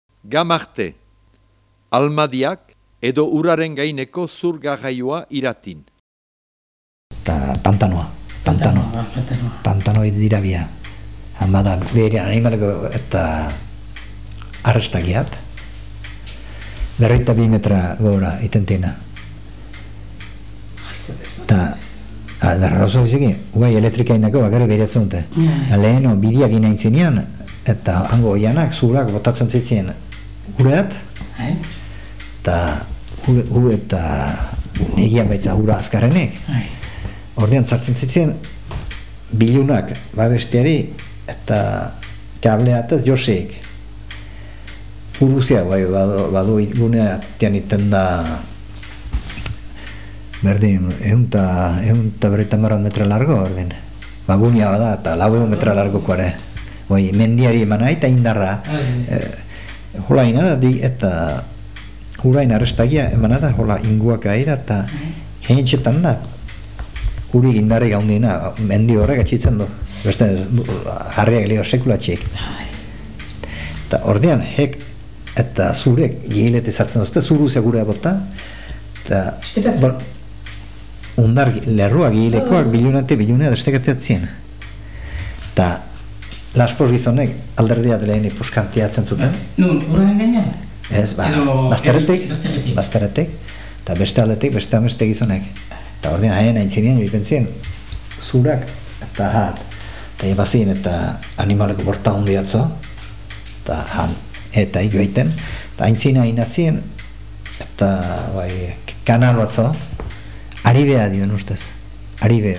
Lekukoa Gamartiarra izanik, udan bortuan artzain egona da Errozateko aldean, mugari hurbil, Orbaizetatik ez urrun. Han zuen ikusi Irati oihanetik behera zurak nola igortzen ziren urez.